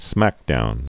(smăkdoun)